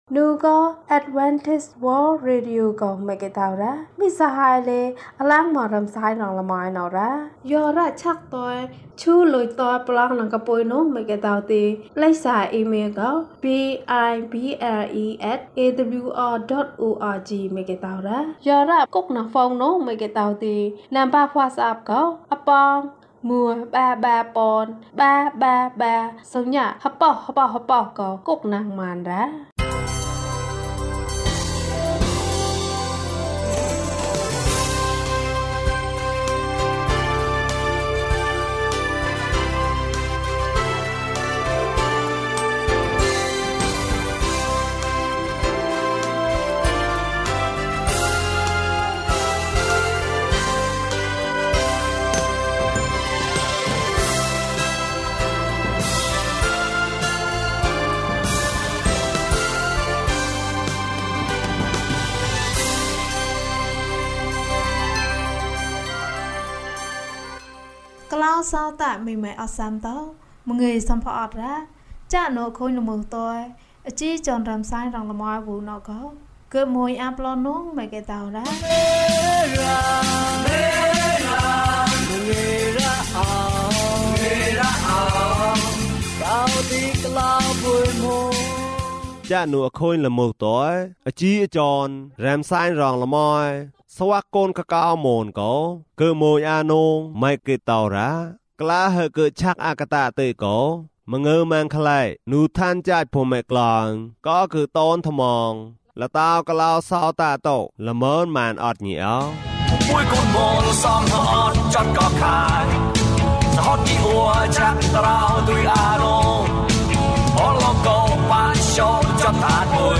ခရစ်တော်ထံသို့ ခြေလှမ်း ၂၆။ ကျန်းမာခြင်းအကြောင်းအရာ။ ဓမ္မသီချင်း။ တရားဒေသနာ။